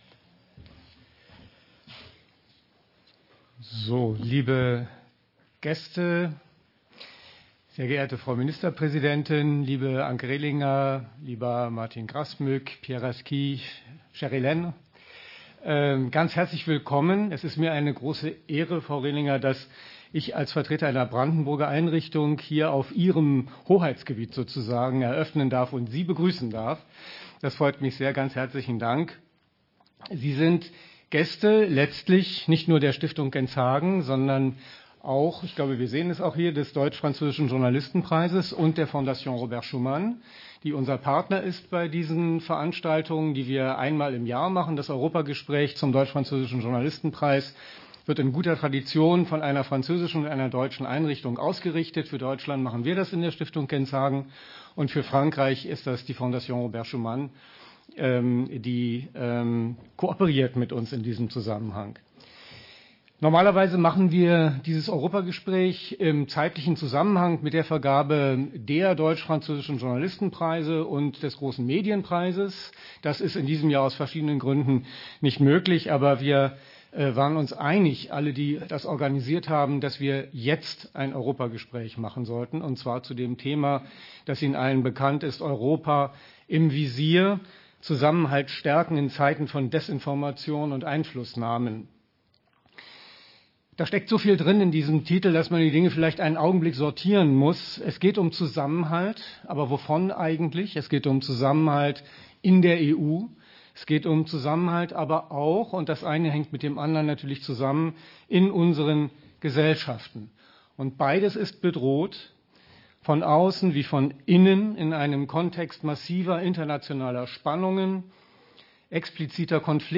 Ein Mitschnitt der Diskussion ist in der Mediathek auf der Website der Stiftung Genshagen verfügbar.